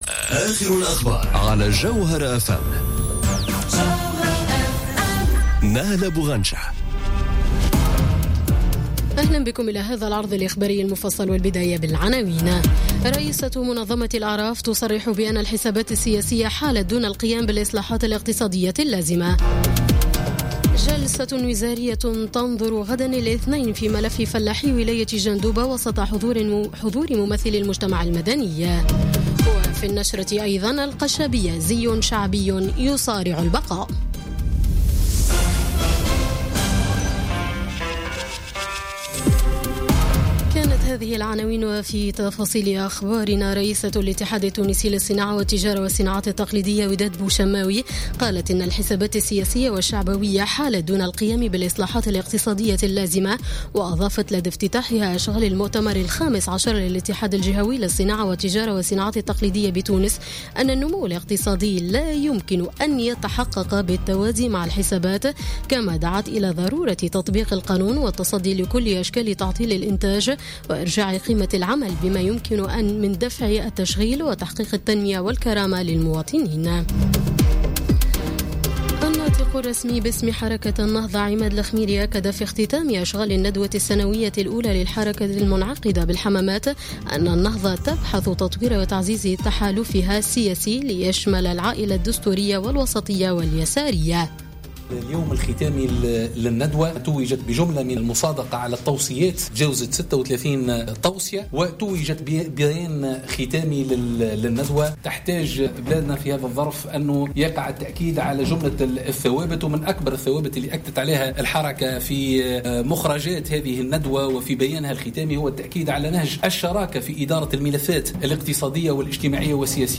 نشرة أخبار السابعة مساء ليوم الأحد 24 ديسمبر 2017